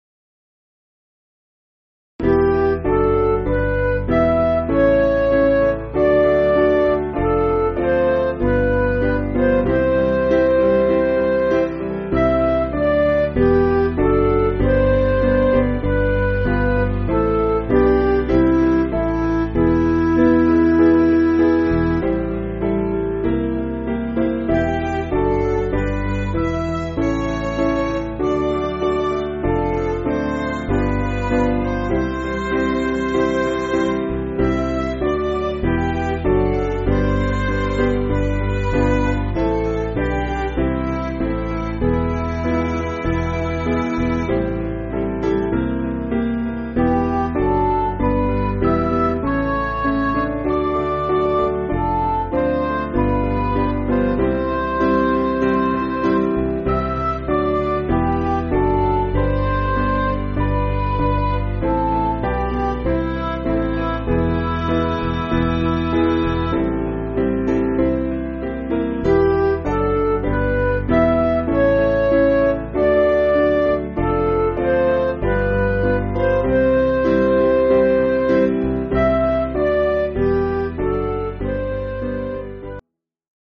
Piano & Instrumental
(CM)   7/Em